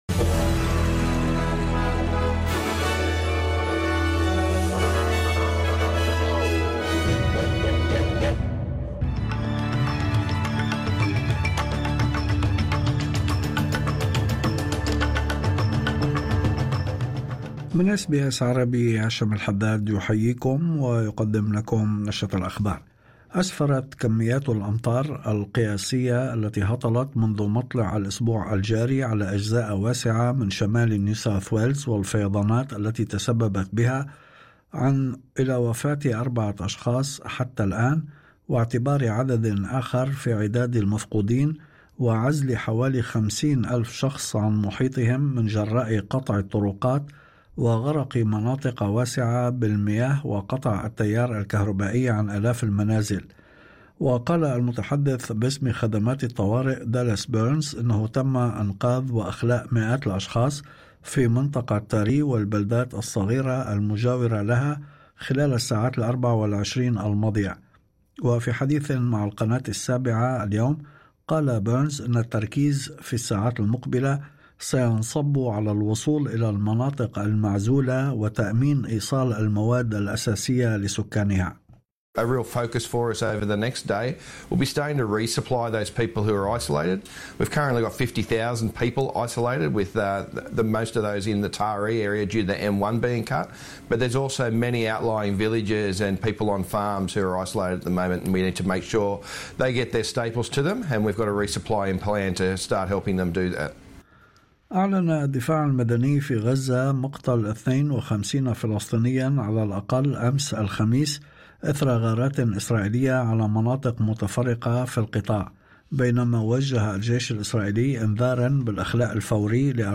نشرة أخبار الظهيرة 23/05/2025